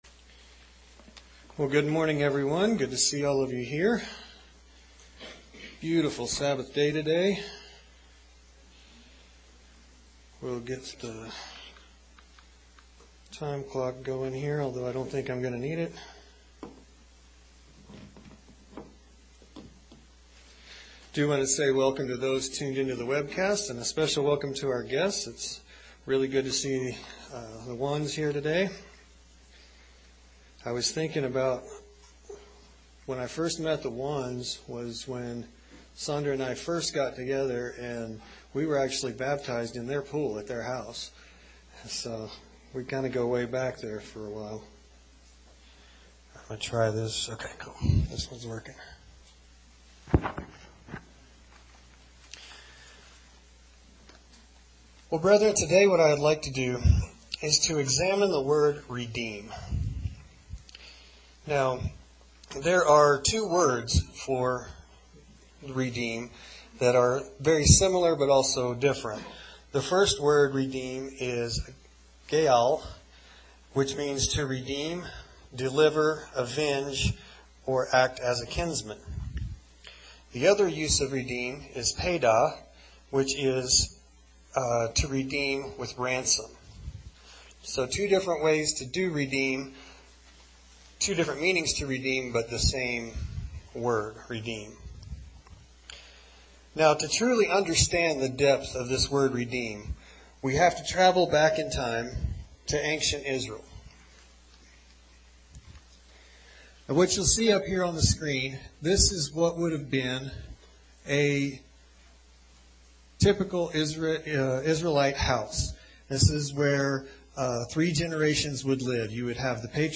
Sermons
Given in Colorado Springs, CO Denver, CO Loveland, CO